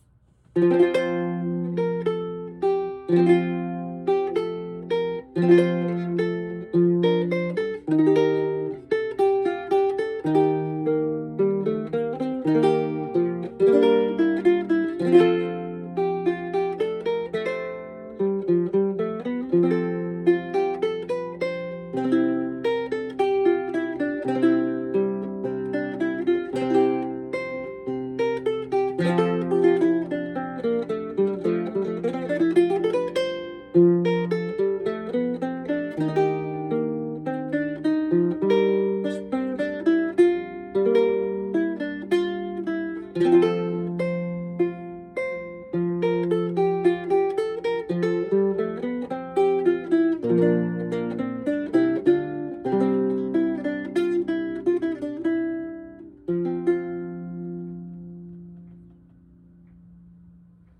2026年1月完成の最新ビウエラ
表はスプルース、裏と横はメイプル、ネックはマホガニー、指板はパープルハート。